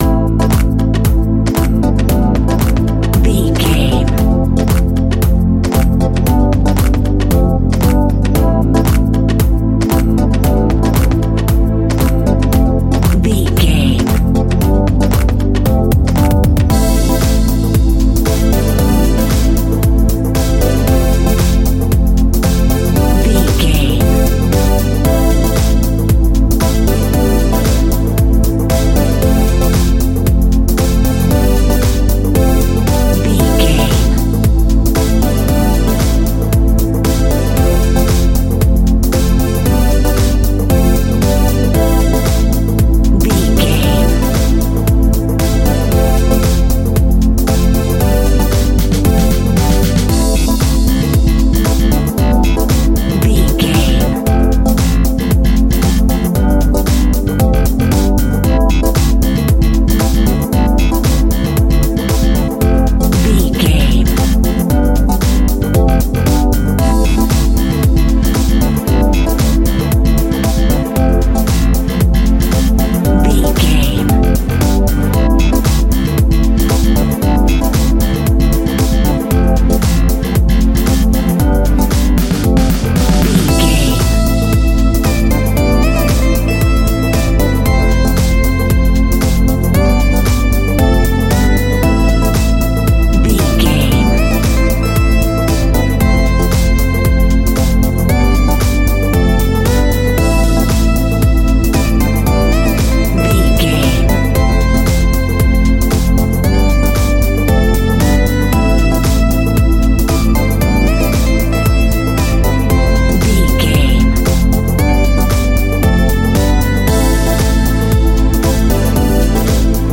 Aeolian/Minor
groovy
uplifting
energetic
synthesiser
drum machine
electric piano
bass guitar
disco
instrumentals